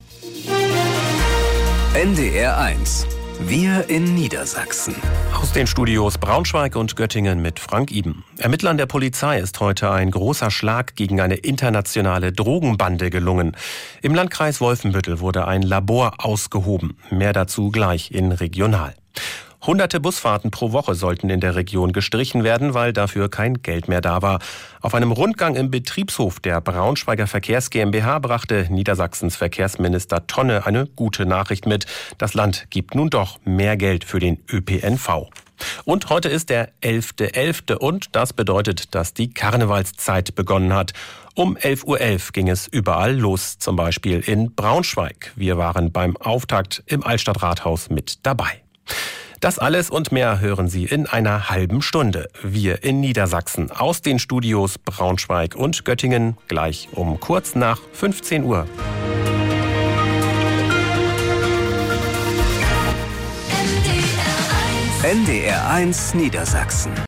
Nachrichten aus dem Studio Braunschweig
Regional-Nachrichten auf NDR 1 Niedersachsen.